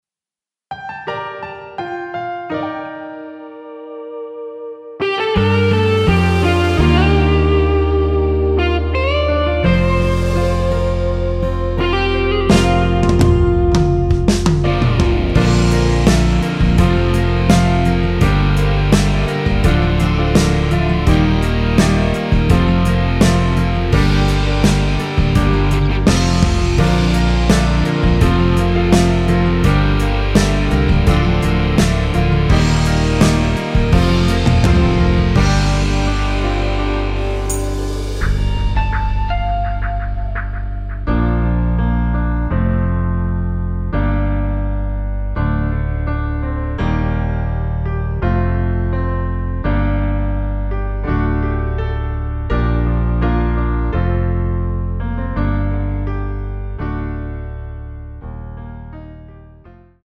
원키에서(+5)올린 MR입니다.
Bb
앞부분30초, 뒷부분30초씩 편집해서 올려 드리고 있습니다.
중간에 음이 끈어지고 다시 나오는 이유는